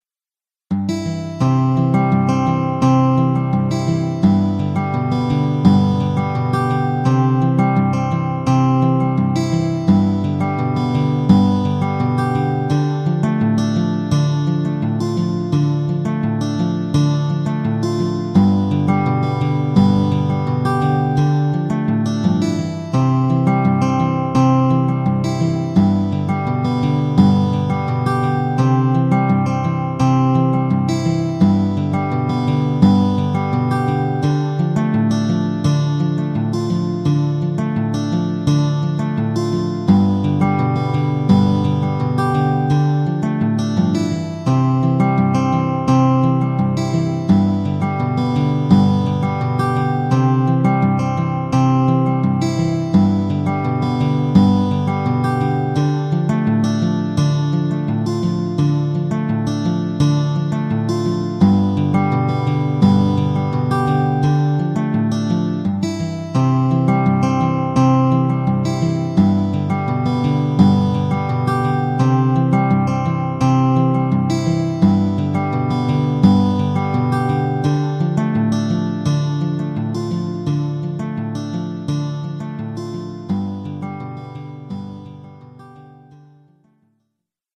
My Garageband acoustic guitar composition from 2011